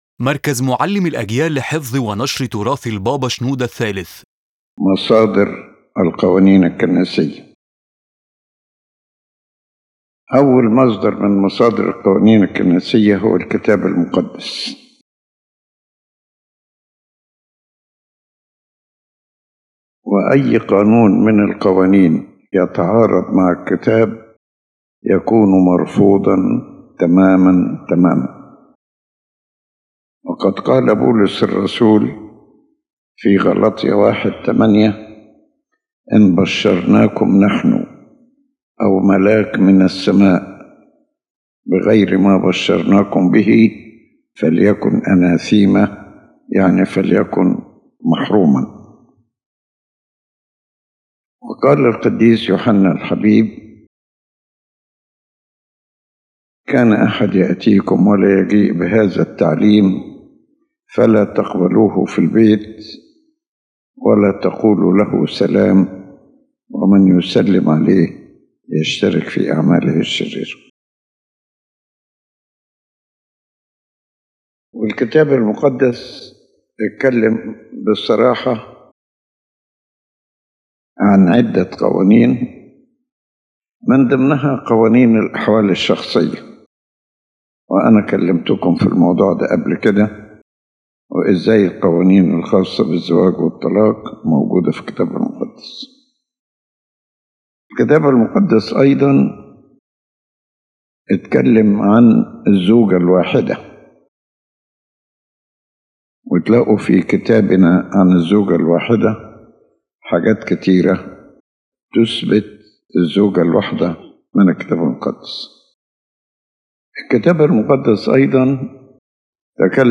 The lecture explains the sources of legislation in the Church and how ecclesiastical laws are derived, emphasizing that any law that contradicts the Word of God is completely rejected. Four main sources are presented: the Holy Bible, the church rites, the teachings of recognized Fathers, and the church traditions.